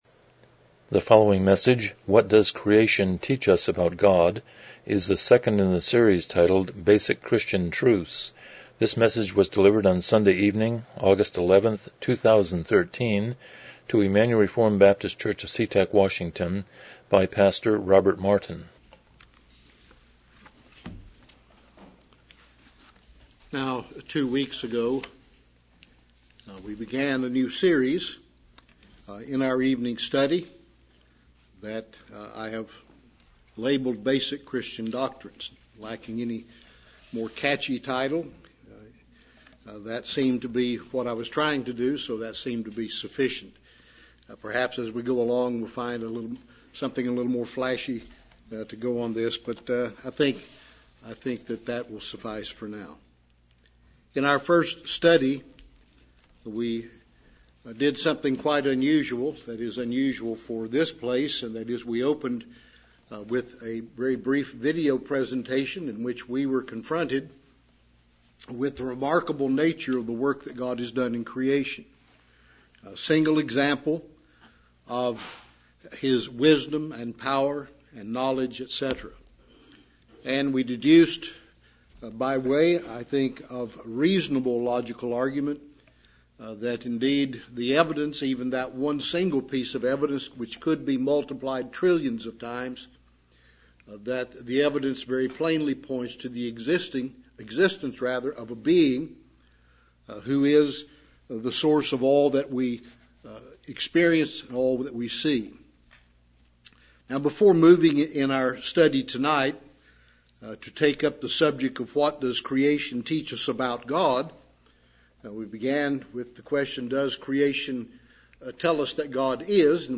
Basic Christian Truths Service Type: Evening Worship « 35 The Sermon on the Mount